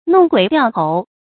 弄鬼掉猴 注音： ㄋㄨㄙˋ ㄍㄨㄟˇ ㄉㄧㄠˋ ㄏㄡˊ 讀音讀法： 意思解釋： 比喻調皮搗蛋。